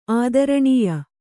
♪ ādaraṇīya